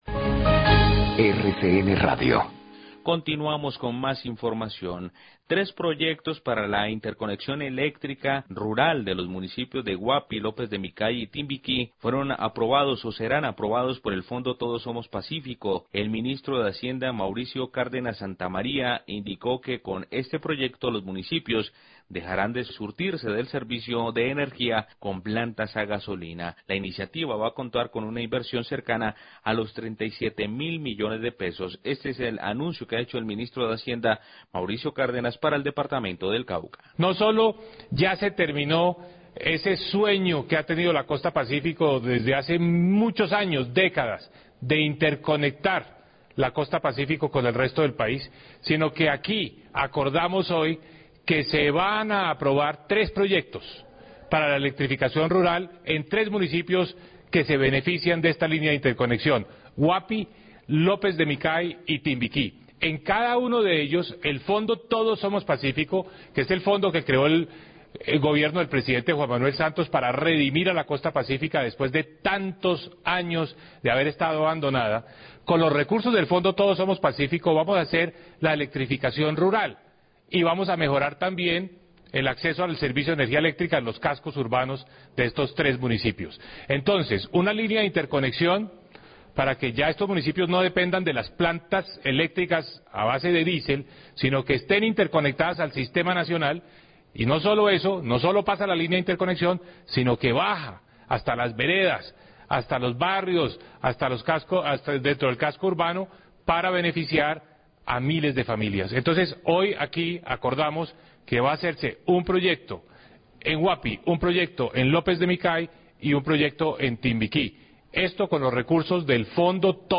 Radio
Tres proyectos de interconexión eléctrica par la costa pacífica caucana serán aprobados por el plan Todos Somos Pazcífico, la iniciativa va a contar con una inversión cercana a los 37 mil millones de pesos. Declaraciones de Mauricio Cárdenas, Ministro de Hacienda.